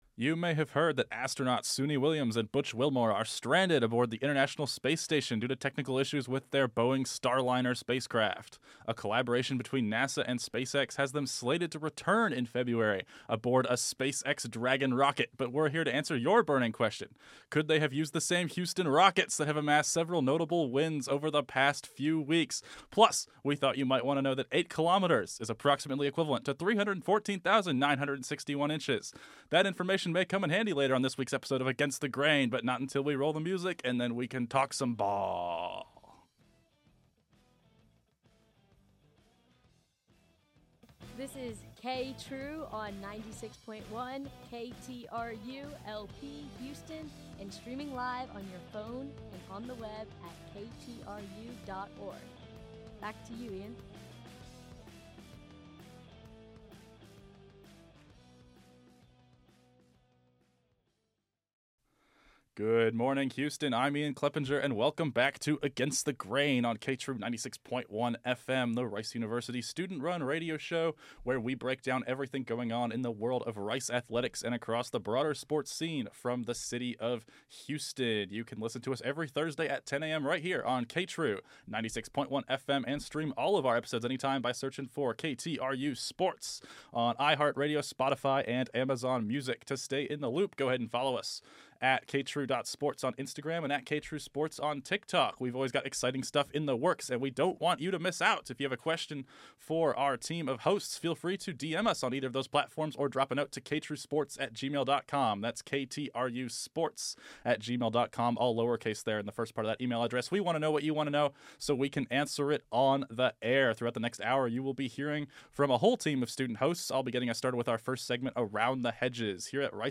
Ceiling for Young Rockets Team + Interview